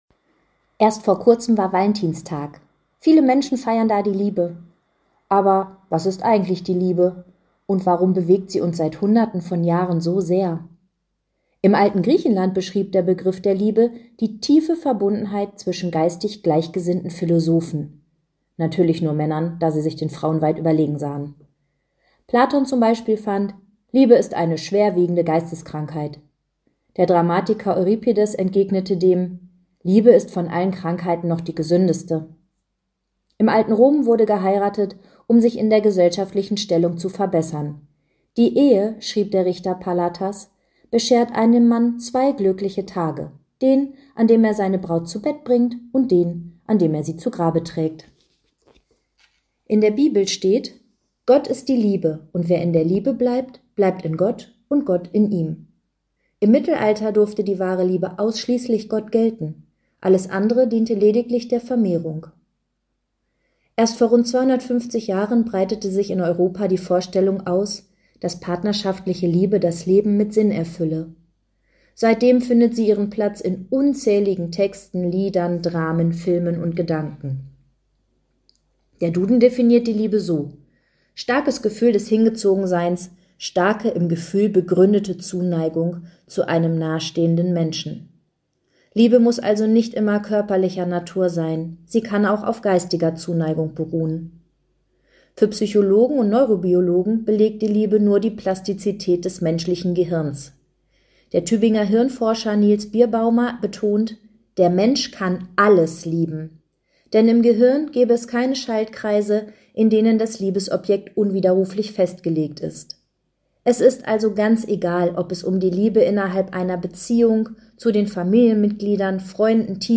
Radioandacht vom 2. März